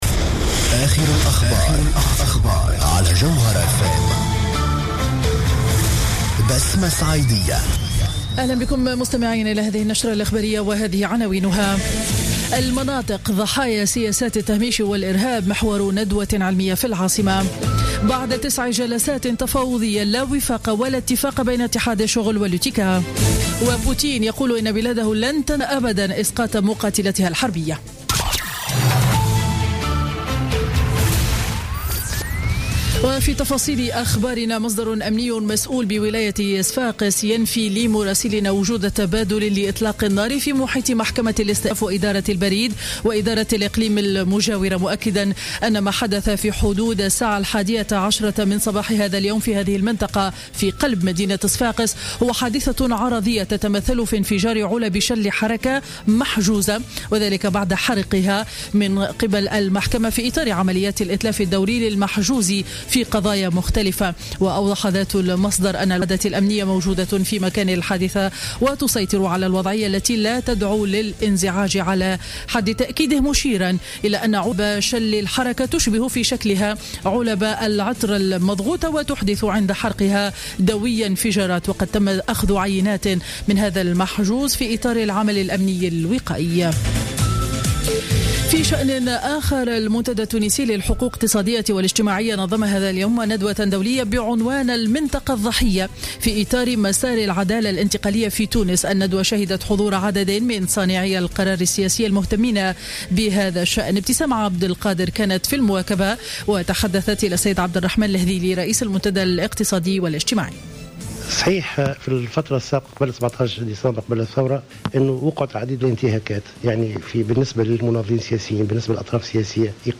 نشرة أخبار منتصف النهار ليوم الخميس 3 ديسمبر 2015